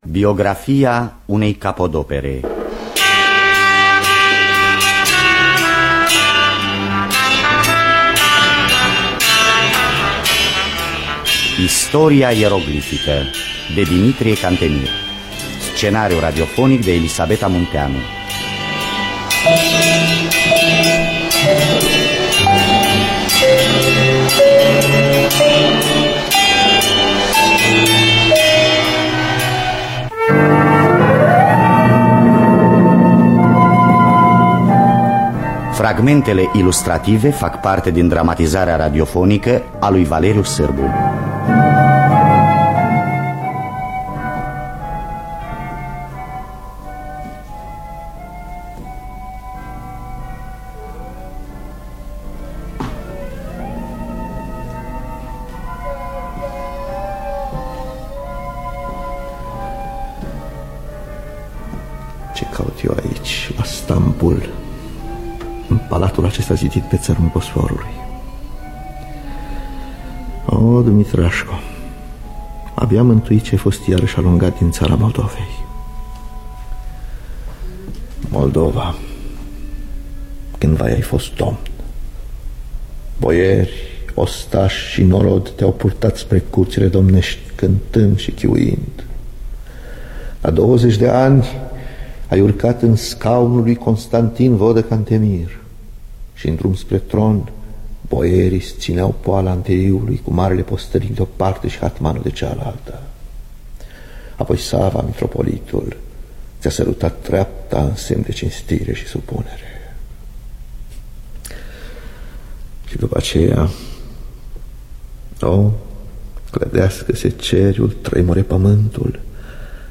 Conține fragmente ilustrative din serialul radiofonic.